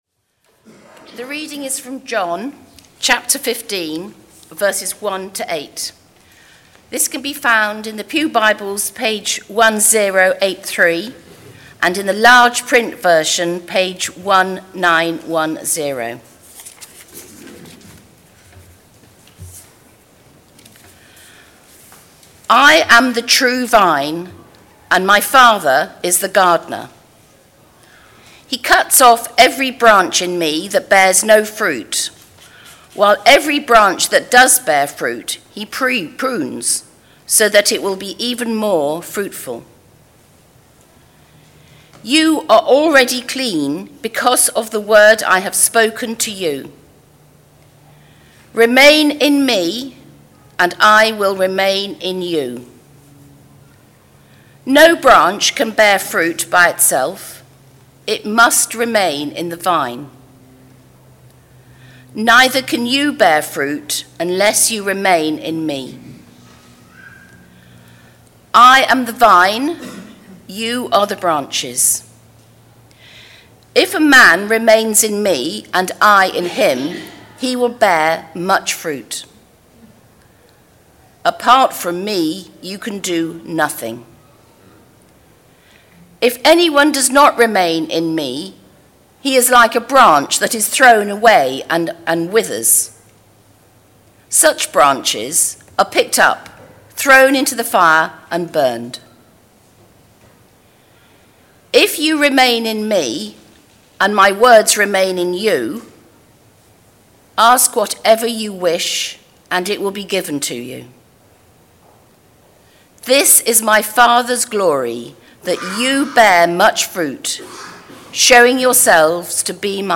Theme: Serving Sunday Sermon